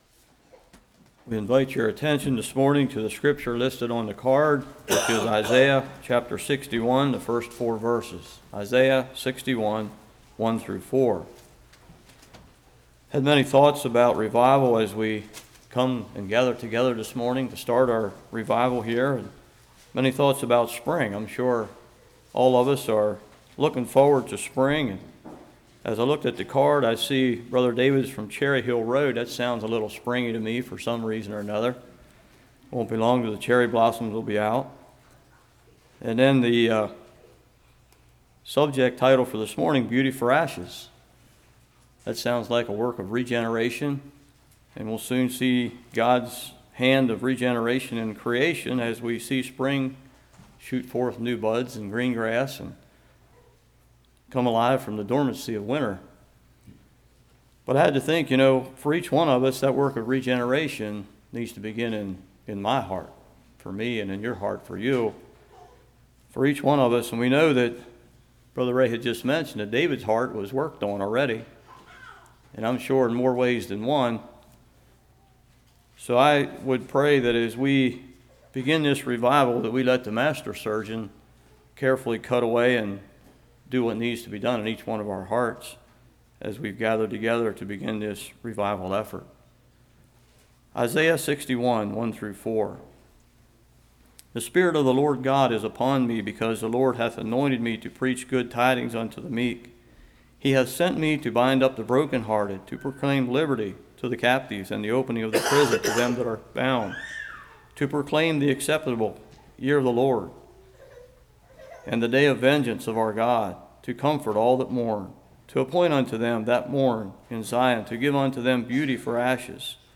Series: Spring Revival 2015 Passage: Isaiah 61:1-4 Service Type: Revival